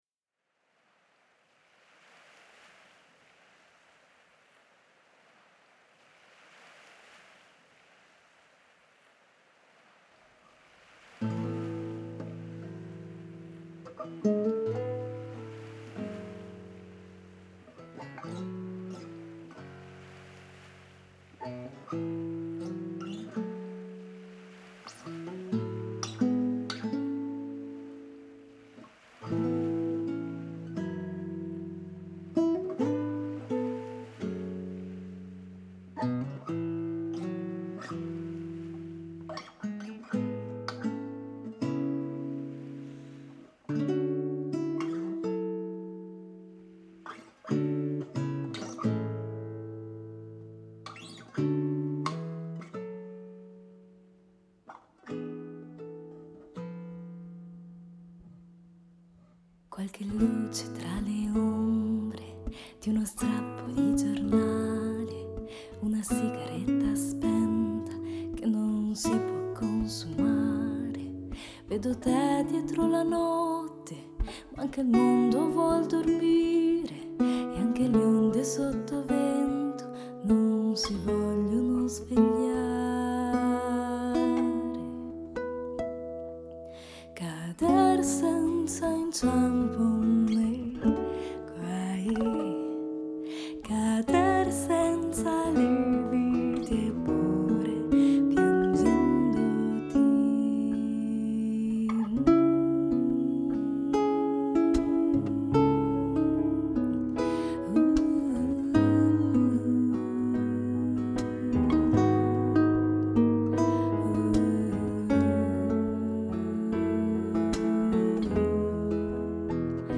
chitarra classica
pianoforte, tastiera